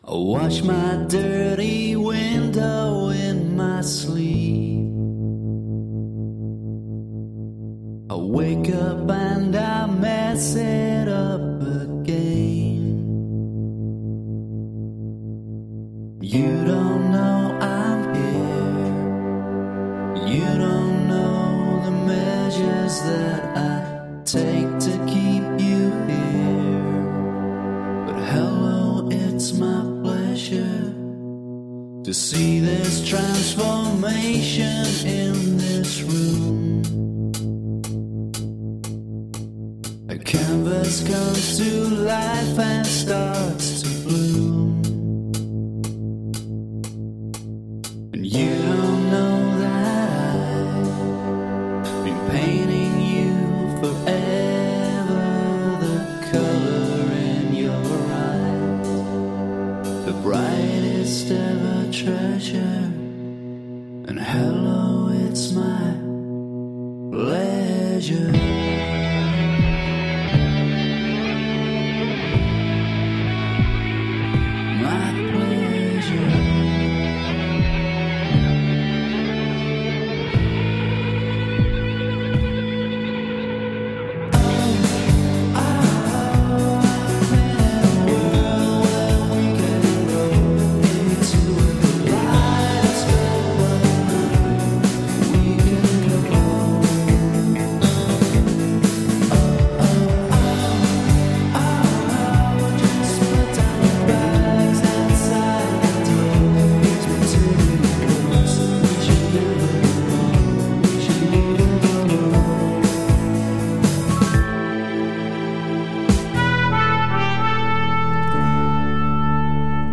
Shimmering indie guitar pop with orchestral moments.